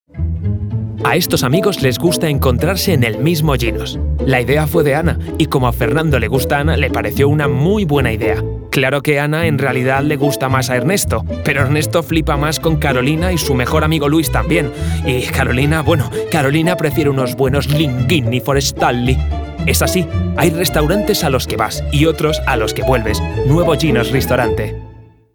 Commercial, Natural, Urban, Cool, Warm
He is characterized by having a special register that can lead to different timbres and tones, and having a voice with a fairly wide tonal range. From a very metallic, plastic, and high-pitched voice, to a much warmer and deeper voice, covering different styles and characters that he can interpret and imitate by having a good ear.